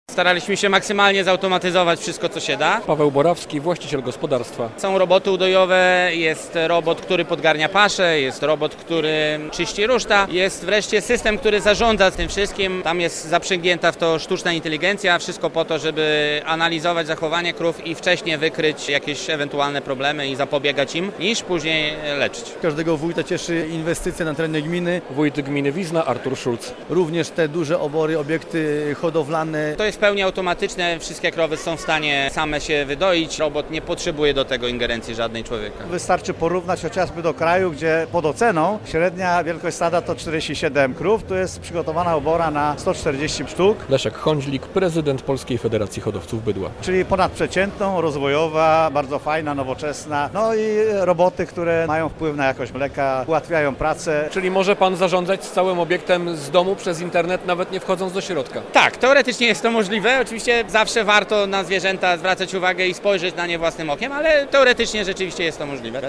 Nowoczesna obora w Rutkach - relacja